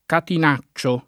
Catinaccio [ katin #©© o ]